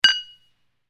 Tiếng Cụng Ly chúc mừng
Created by: Tiếng cụng ly rượu, ly bia
Thể loại: Tiếng động
Tiếng ly thủy tịnh chạm vào nhau tạo nên tiếng động nghe vui tai cảm giác như nghe một bản nhạc vui tươi do con người tạo ra.
Tieng-cung-ly-www_tiengdong_com.mp3